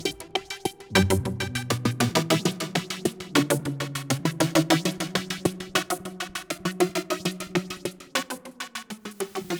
• Bass Sequence texture.wav
Bass_Sequence_texture__OZX.wav